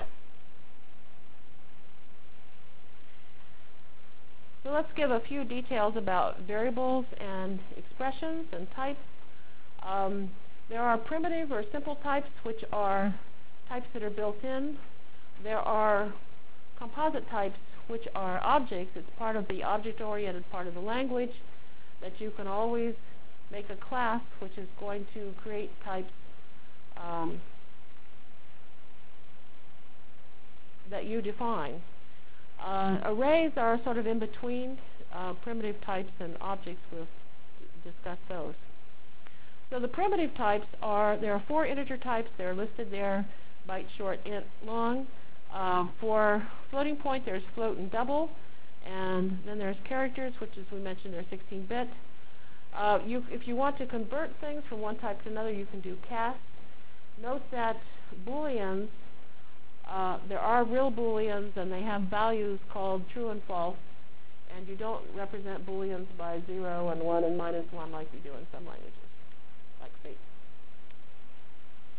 From Jan 22 Delivered Lecture for Course CPS616 -- Java Lecture 1 -- Overview CPS616 spring 1997 -- Jan 22 1997.